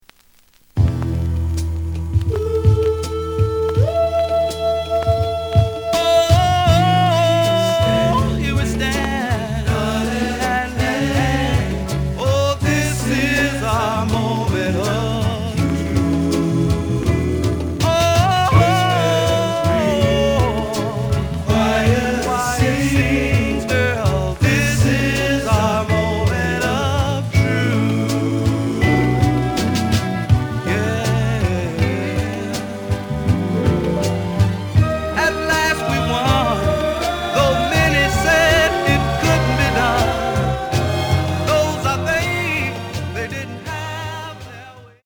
The listen sample is recorded from the actual item.